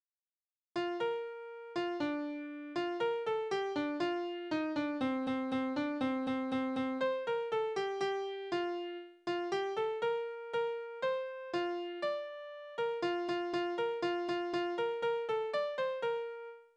Liebeslieder:
Tonart: B-Dur
Taktart: 4/8
Tonumfang: große None
Besetzung: vokal